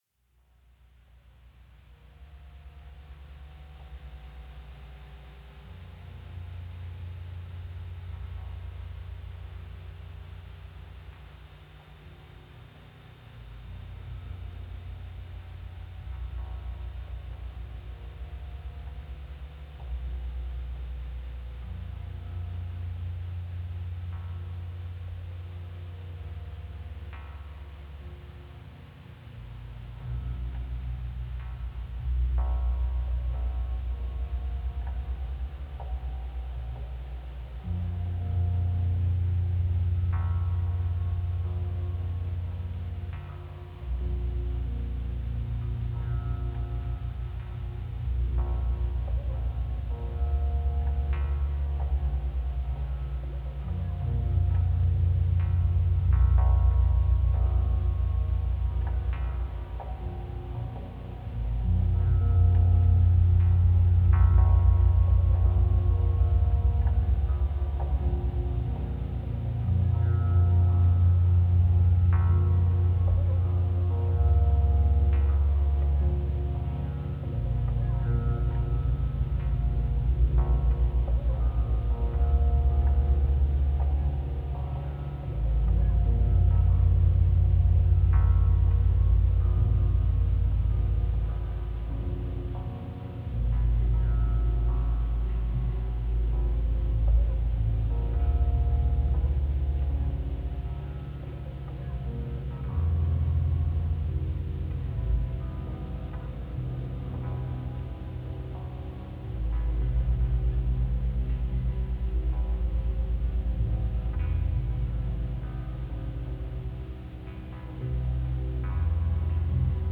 Todos estos sonidos fueron obtenidos de grabaciones en vivo.